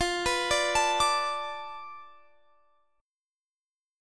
F6b5 Chord
Listen to F6b5 strummed